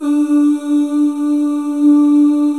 Index of /90_sSampleCDs/Club-50 - Foundations Roland/VOX_xMaleOoz&Ahz/VOX_xMale Ooz 1M